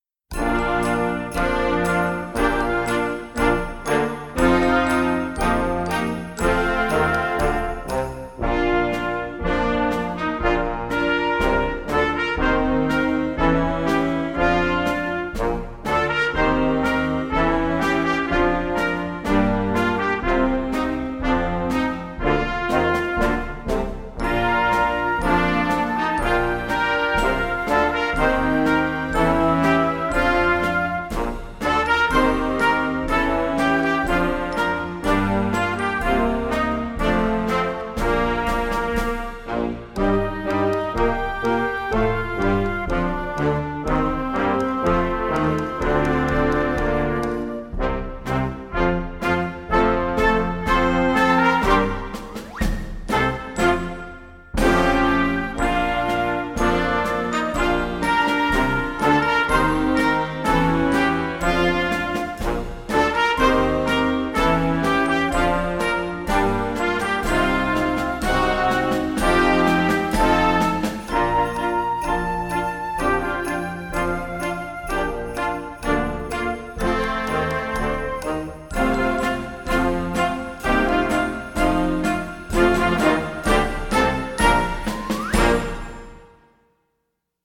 Gattung: Jugendwerk für Weihnachten
Besetzung: Blasorchester